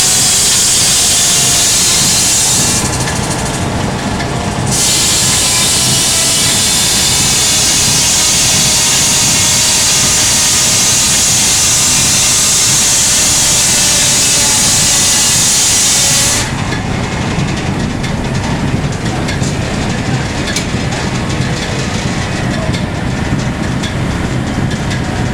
Kraften från vattenturbinen vibrerade hela byggnaden med ett dovt ljud. Vattenturbinen snurrade sågklingan snabbt samt matade fram stocken.